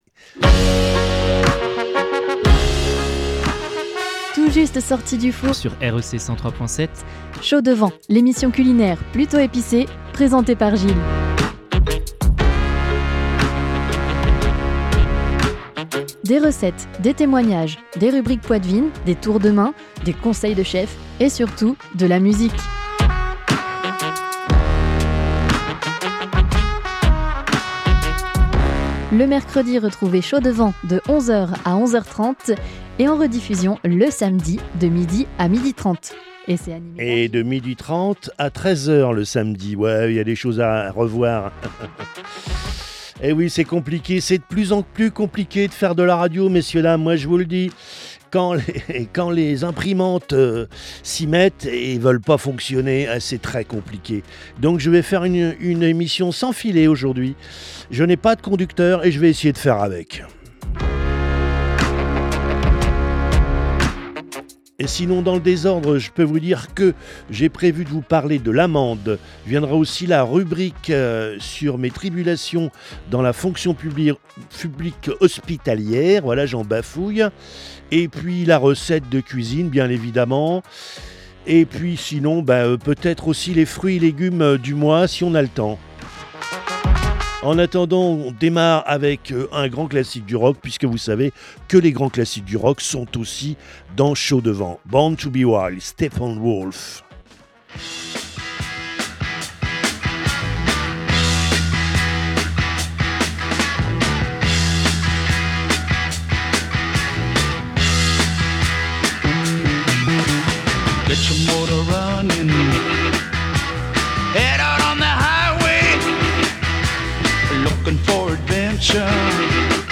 avec anecdotes, témoignages , rubriques , recettes avec des conseils de chef et forcément de la musique !